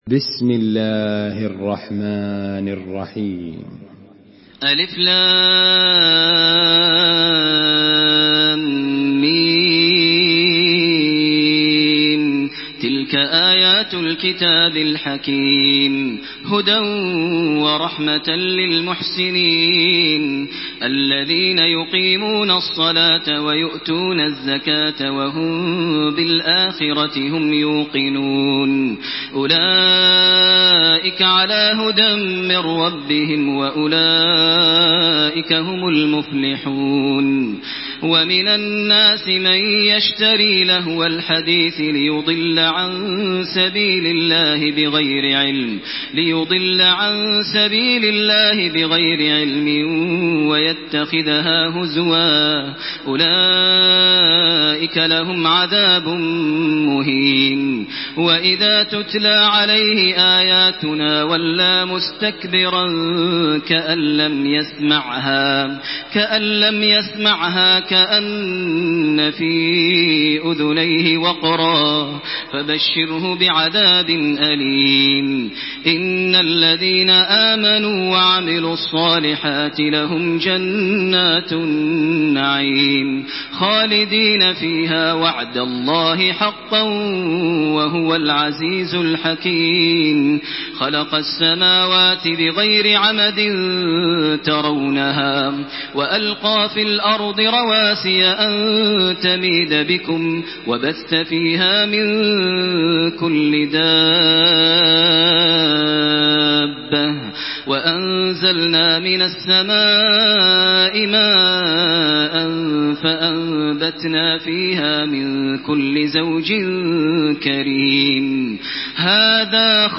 تحميل سورة لقمان بصوت تراويح الحرم المكي 1428
مرتل